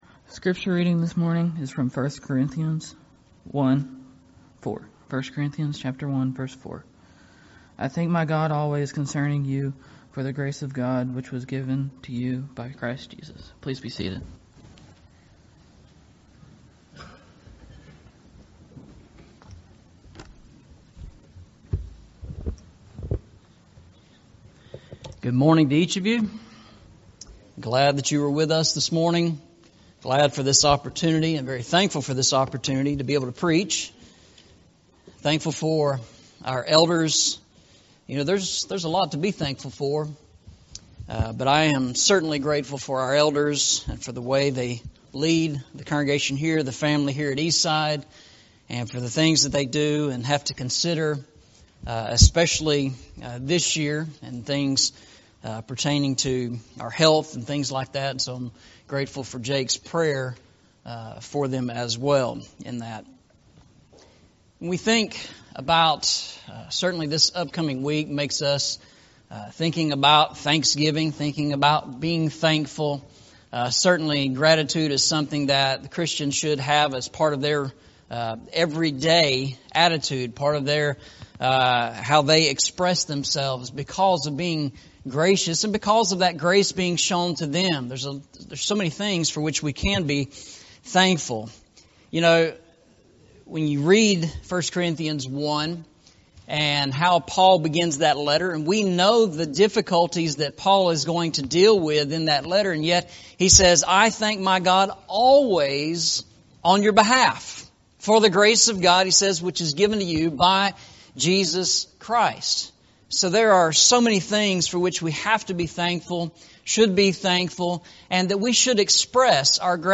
Eastside Sermons Service Type: Sunday Morning « Seeing God